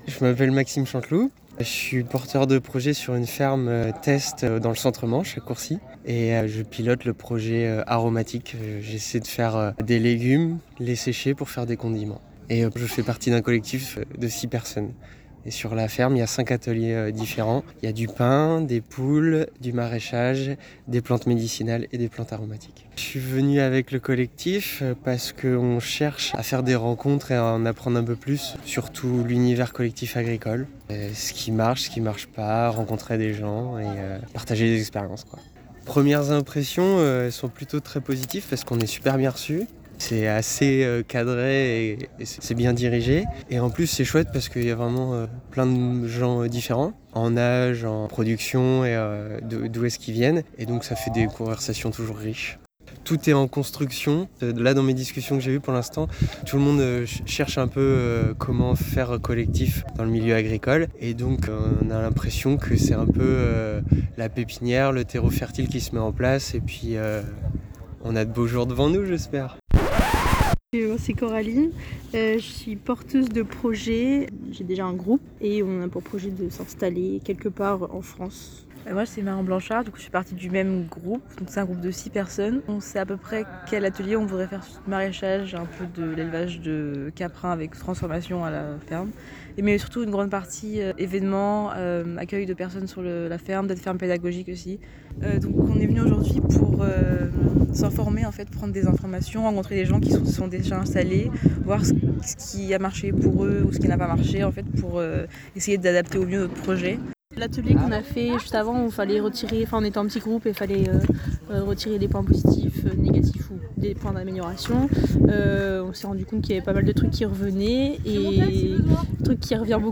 Les reportages sur le terrain
Microtrottoir Cultivons le collectif